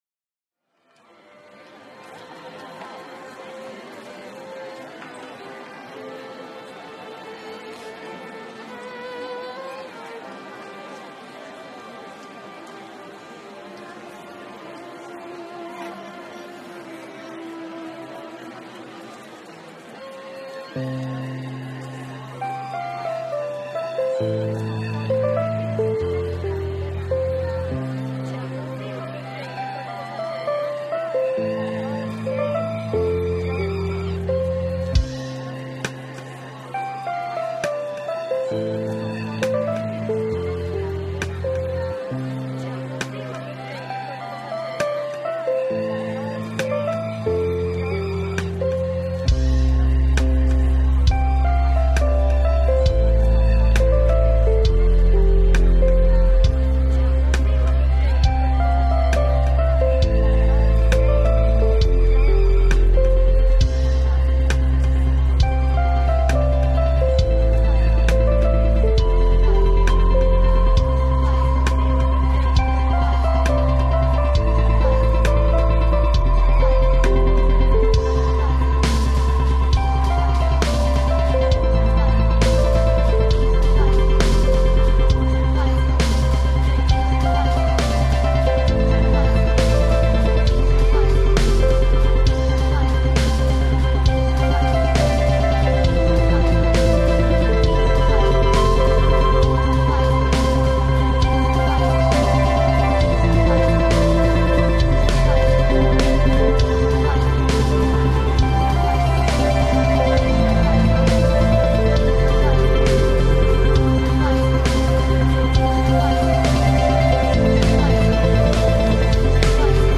Piazza della Repubblica in Florence reimagined